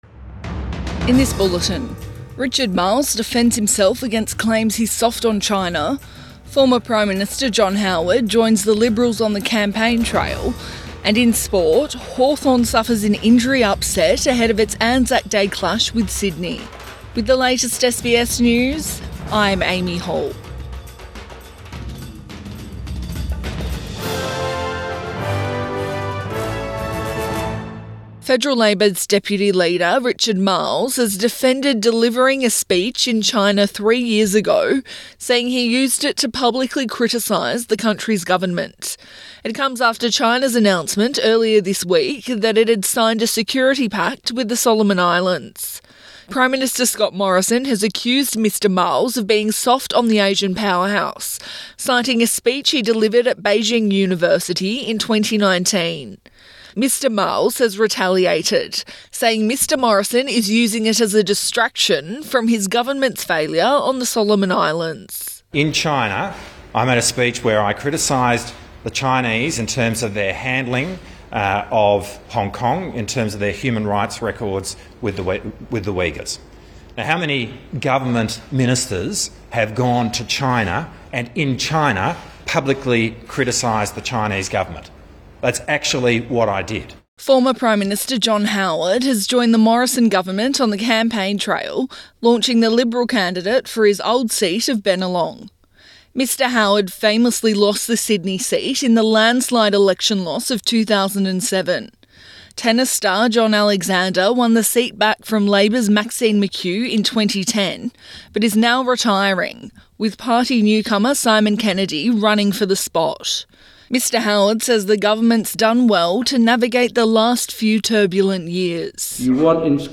PM bulletin 23 April 2022